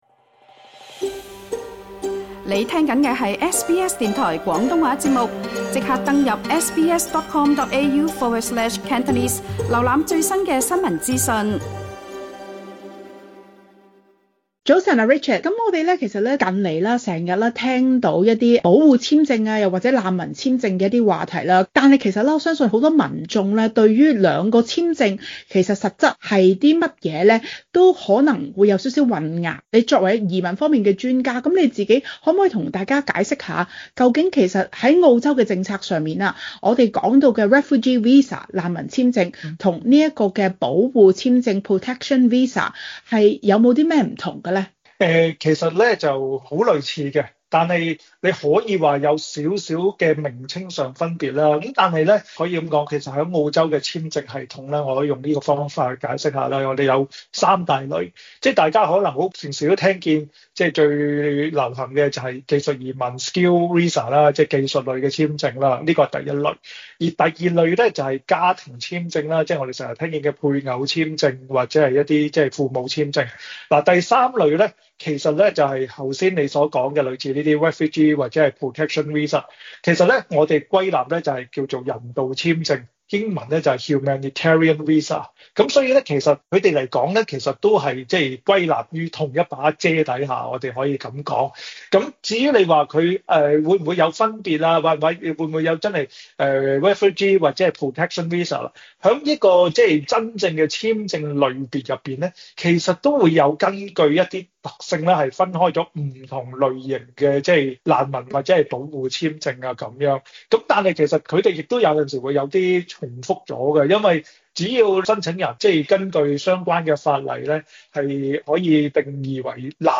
詳情請收聽此節訪問。